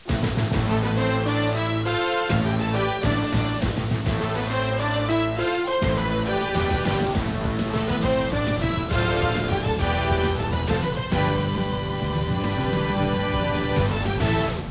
hockey_music.wav